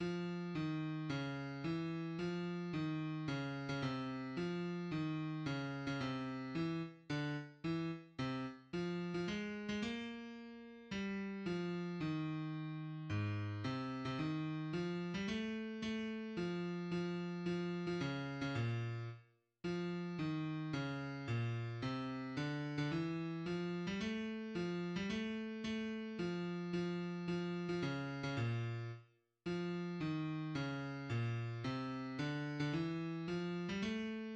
third verse
{\clef bass \tempo 4 = 110 \key aes \major \set Score.currentBarNumber = #13 \bar "" f ees des e f ees des8. des16 c4 f ees des8. des16 c4 e-. d-. e-. c-. f8. f16 aes8. aes16 bes2 g4 f ees2 aes,4 c8. c16 ees4 f8. g16 a4 a f f f8. f16 des8. des16 bes,4 r4 f ees des bes, c d8. d16 e4 f8. g16 a4 f8. g16 a4 a f f f8. f16 des8. des16 bes,4 r4 f ees des bes, c d8. d16 e4 f8. g16 a4 }\addlyrics {\set fontSize = #-2 - - - - Zog nit key - nmol, Zog nit key - nmol, Zog nit key- nmol geyst dem let- stn veg Him- len far- shteln bloy- e teg Ku- men vet nokh un- zer oys- ge- benk- te sho Trot mir zayn- en do zayn- en do } \addlyrics {\set fontSize = #-2 un- zer trot Ge- shri- ben is dos lid blut un nit mit blay iz dos lid mit blut un nit mit blay Fun a foy gl oyf der fray, S'hot a folk ts'- vi- shn fa- ln- di- ke vent, Mit na- gan- es hent in di hent }\midi{}